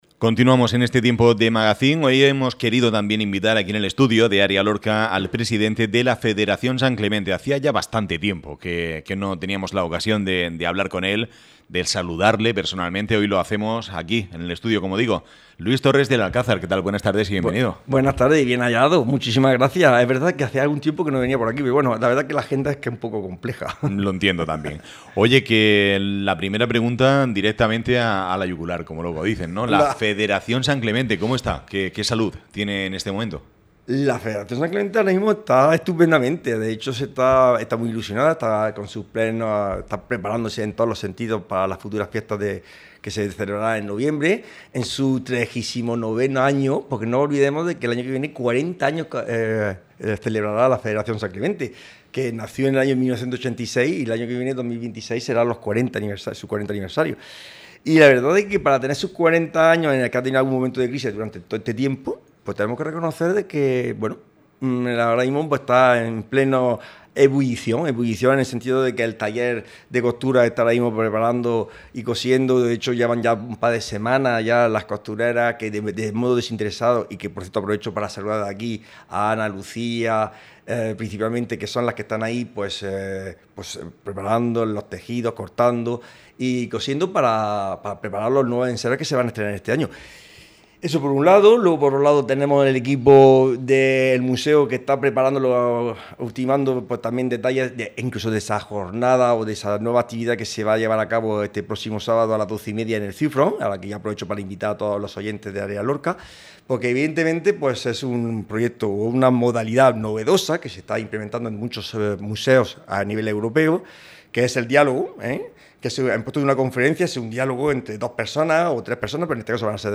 ÁREA LORCA RADIO.